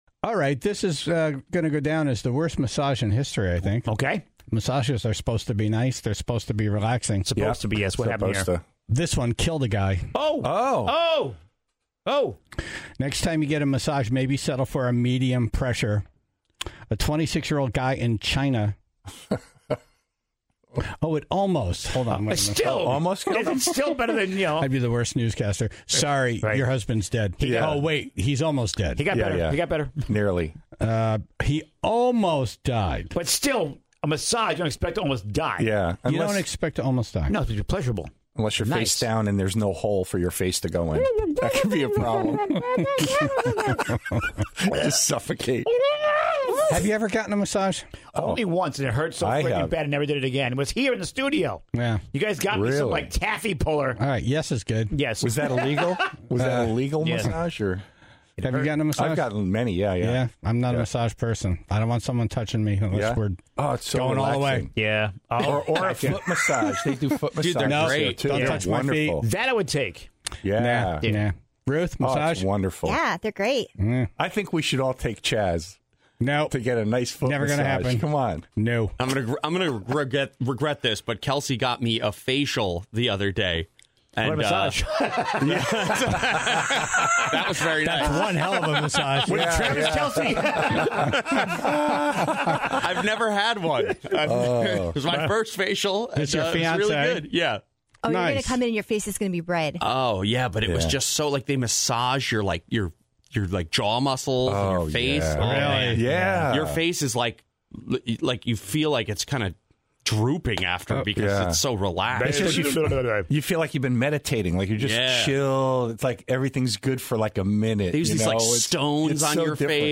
relaxing ASMR